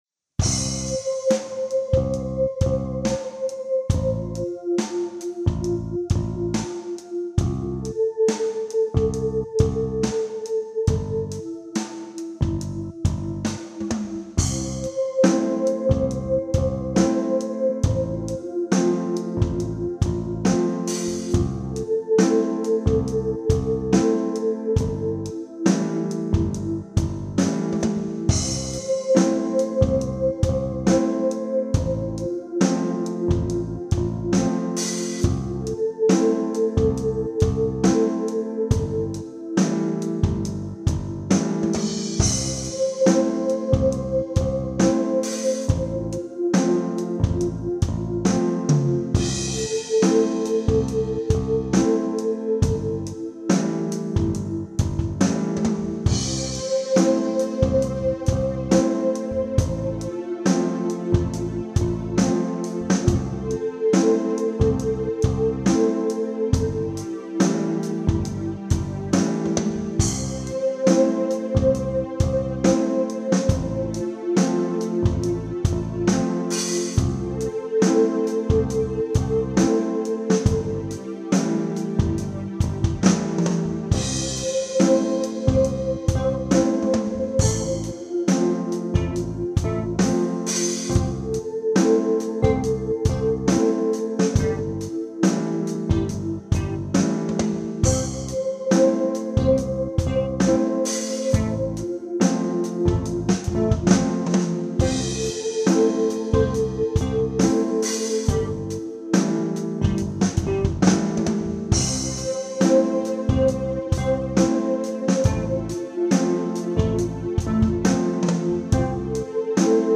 Vielen Dank für euer Feedback! Hier mein Take (der sechste oder so) von gestern, noch ohne Einflüsse von euch, die ihr dankenswerterweise schon etwas reingestellt habt: